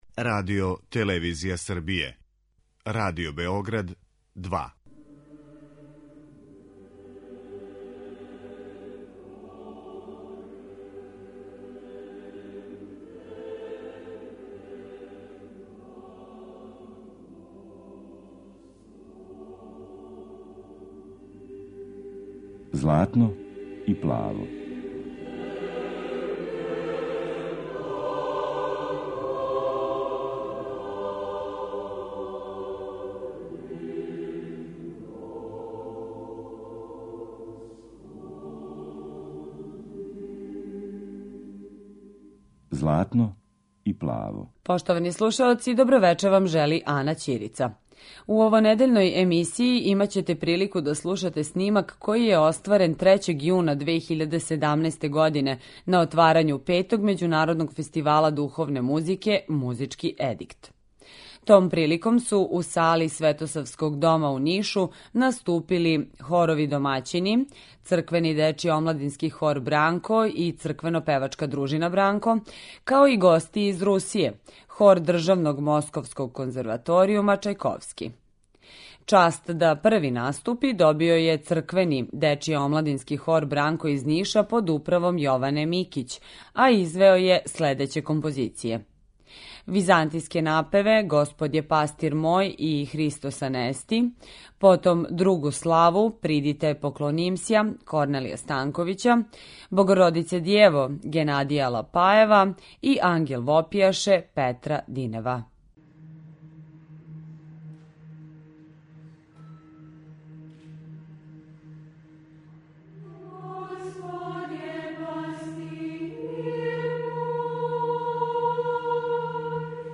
Овонедељну емисију посвећујемо Петом међународном фестивалу хорске духовне музике 'Музички едикт', који је одржан од 3-6.јуна 2017.године у Нишу.
Емитоваћемо снимак који је остварен на отварању манифестације
Емисија посвећена православној духовној музици.